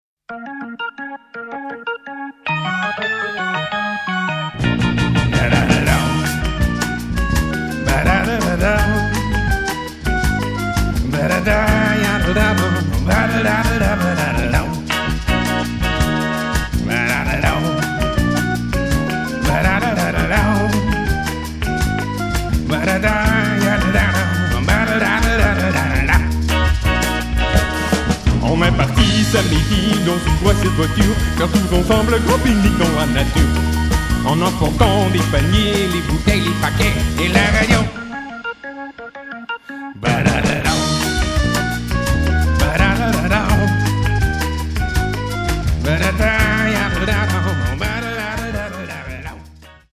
französischen Sixties-Beat, charmante Pop-Songs